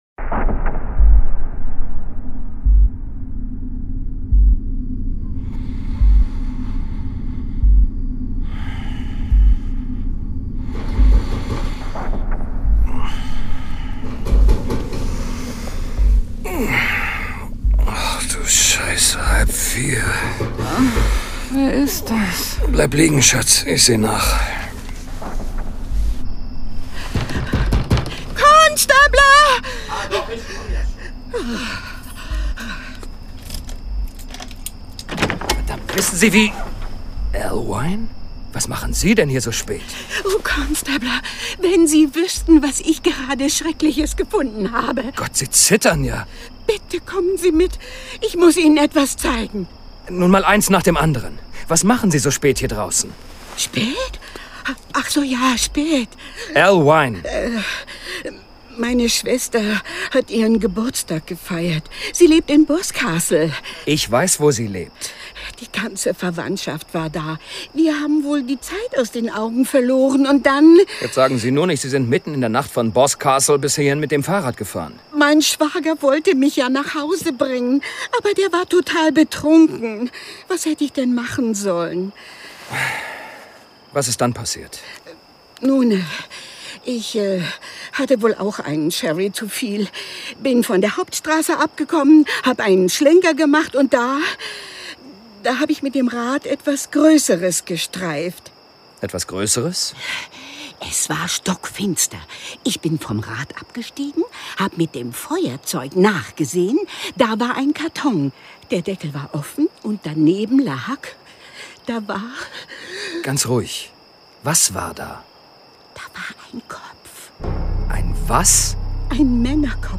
John Sinclair Classics - Folge 3 Dr. Satanos. Hörspiel. Jason Dark (Autor) Wolfgang Pampel (Sprecher) Audio-CD 2010 | 3.